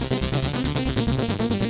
Index of /musicradar/8-bit-bonanza-samples/FM Arp Loops
CS_FMArp B_140-C.wav